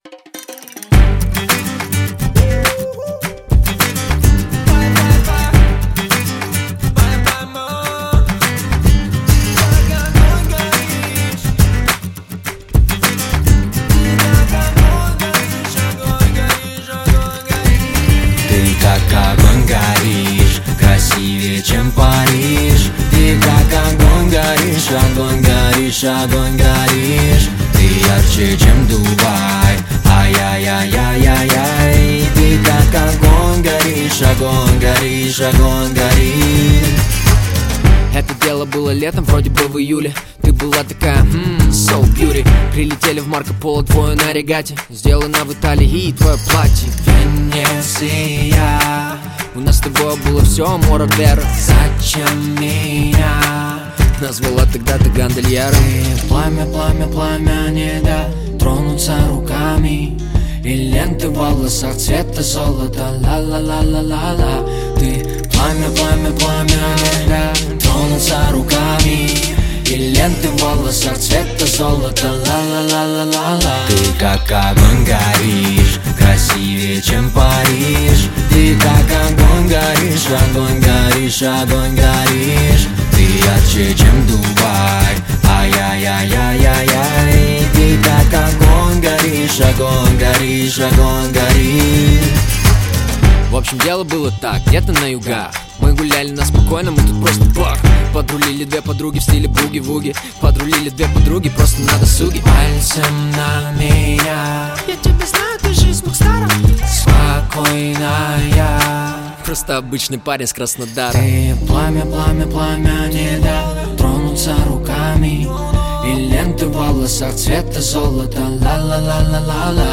Русский рэп
Жанр: Русский рэп / R & B